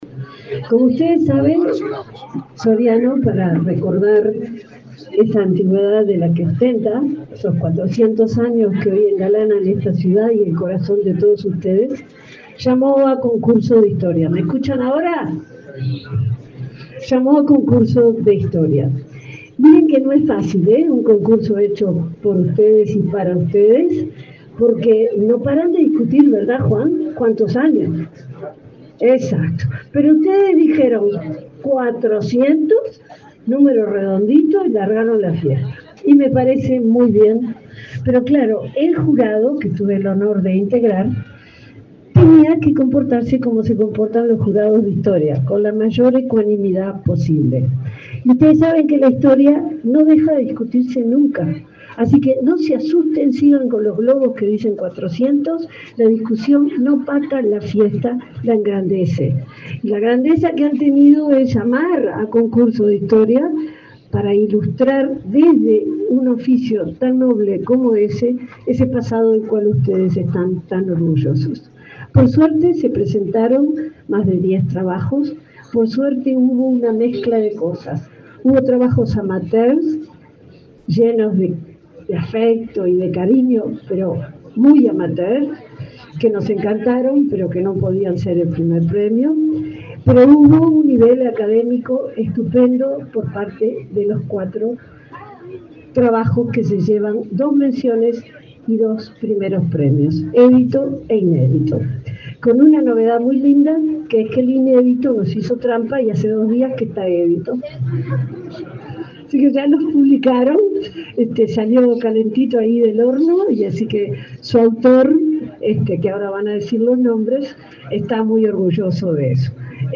Palabras de la subsecretaria de Educación y Cultura, Ana Ribeiro
La subsecretaria de Educación y Cultura, Ana Ribeiro, participó, este sábado 10 en Villa Soriano, en los festejos por los 400 años de esa localidad